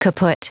Prononciation du mot kaput en anglais (fichier audio)
Prononciation du mot : kaput